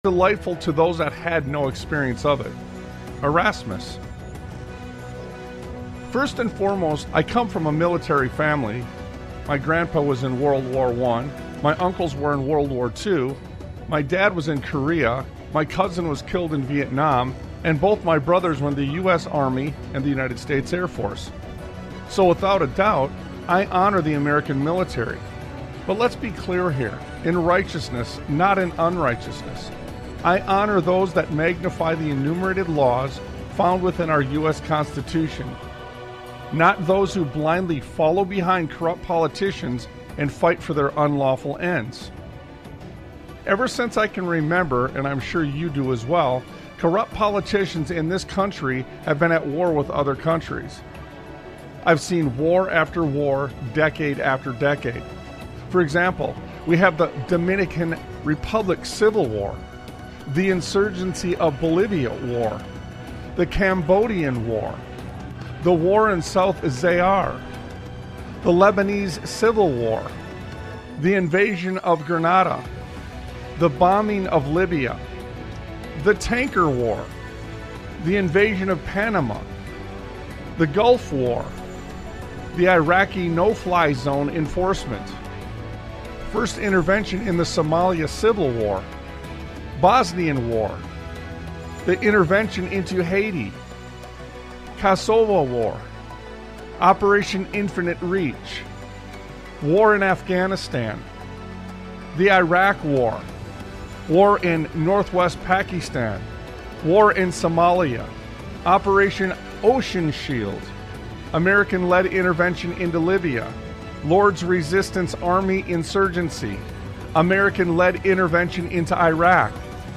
Talk Show Episode, Audio Podcast, Sons of Liberty Radio and Incremental Downloads on , show guests , about Incremental Downloads,The Illusion of Law,Sovereignty,The Cost of War,THE CONSTITUTIONAL AUDIT,Domestic Tyranny,the Uni-Party Narrative,Controlled Opposition,The Mike Lindell Controversy,Baby Christians, categorized as Education,History,Military,News,Politics & Government,Religion,Christianity,Society and Culture,Theory & Conspiracy